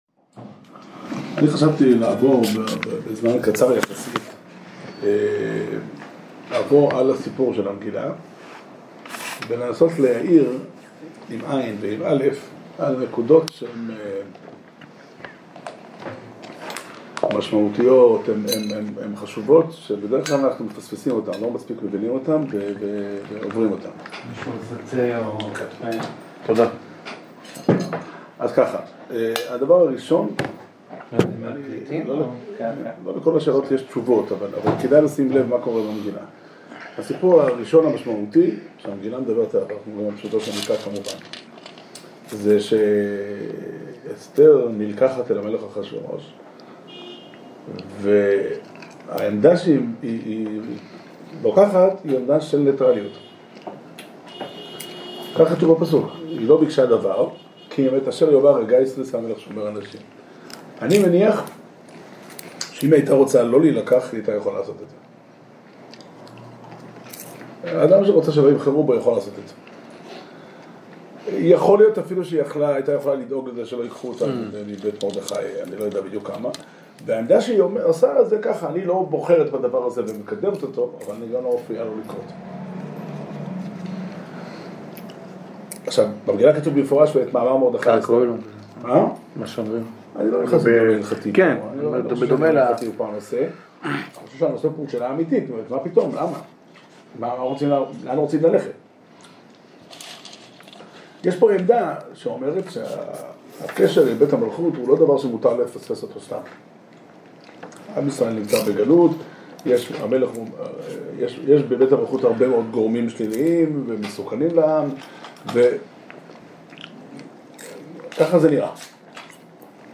שיעור שנמסר בבית המדרש פתחי עולם בתאריך י"א אדר תשע"ח